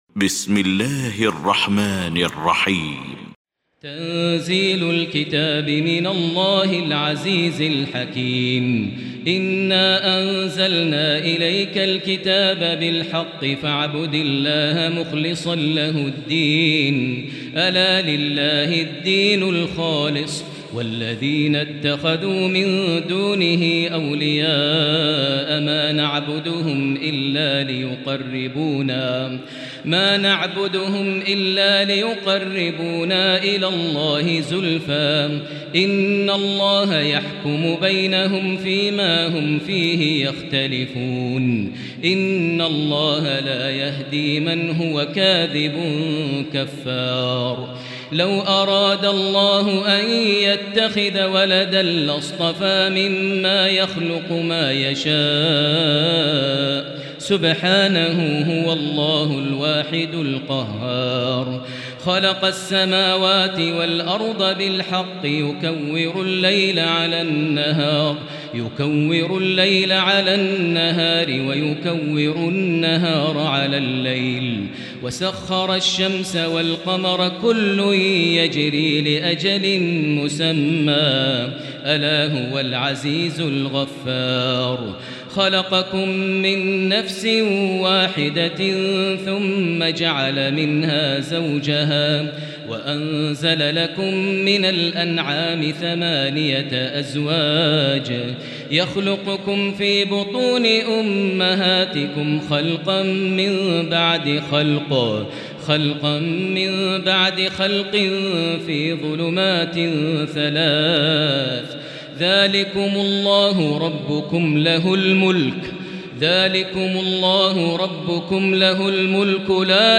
المكان: المسجد الحرام الشيخ: معالي الشيخ أ.د. عبدالرحمن بن عبدالعزيز السديس معالي الشيخ أ.د. عبدالرحمن بن عبدالعزيز السديس فضيلة الشيخ عبدالله الجهني فضيلة الشيخ ماهر المعيقلي الزمر